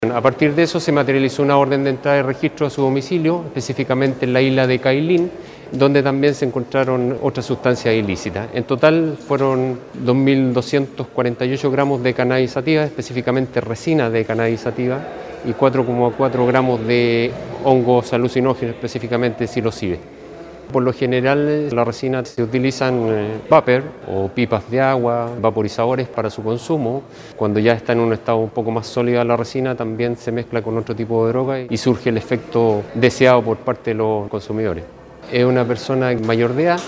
Señaló el oficial de la policía civil que entre otros elementos que se descubrieron de esta persona es que utilizó un nombre falso o ficticio, para retirar la encomienda desde la oficina respectiva en la ciudad de Quellón.